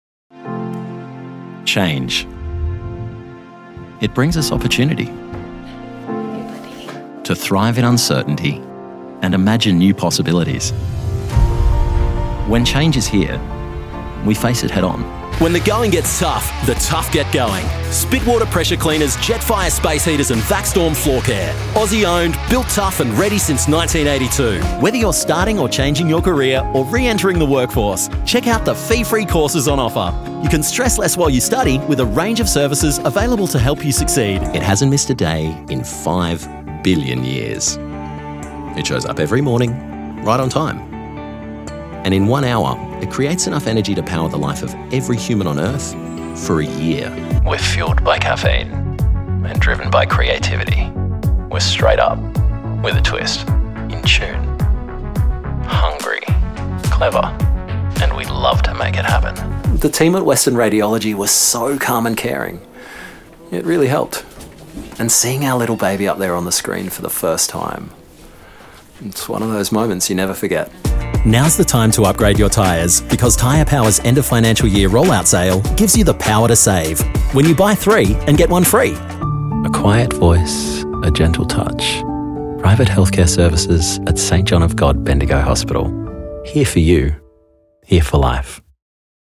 In my natural Australian accent I strike a friendly, intelligent, warm and energetic tone suitable for an upbeat commercial sound or a more sophisticated timbre for e-learning.